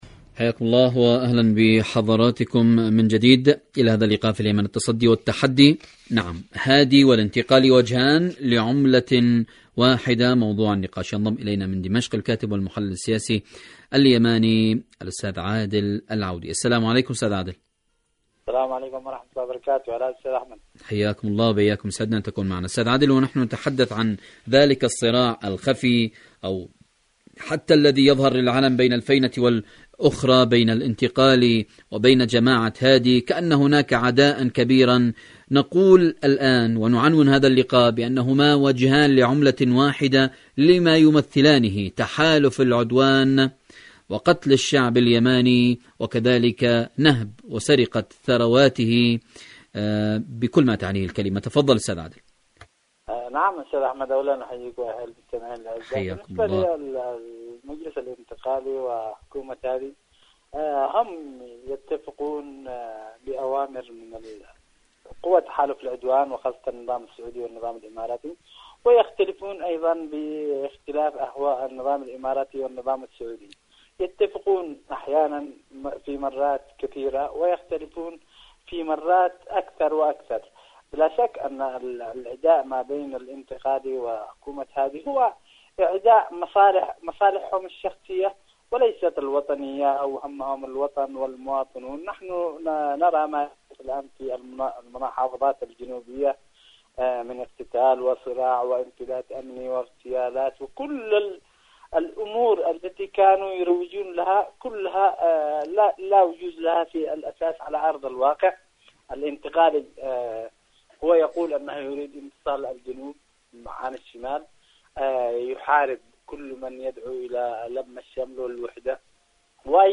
مقابلات إذاعية حركة أنصار الله